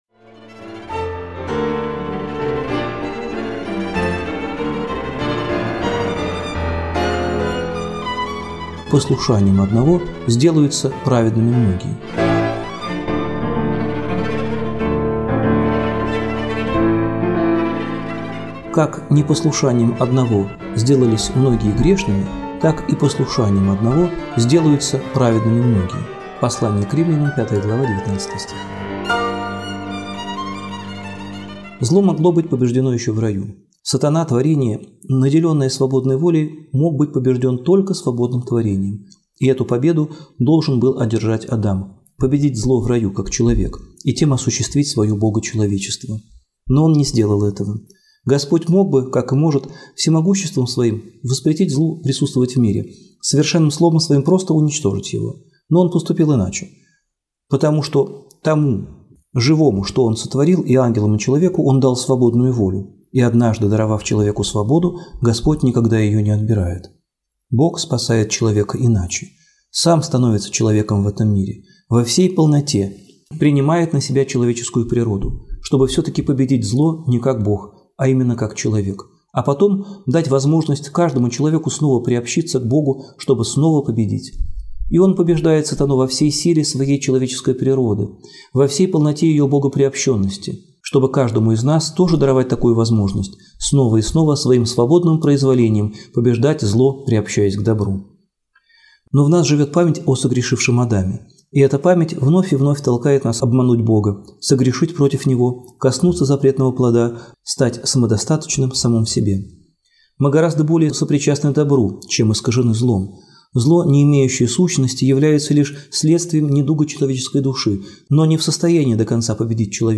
Аудиобеседы на 10-20 минут, сопровождаемые текстом — можно успеть прослушать даже между делом.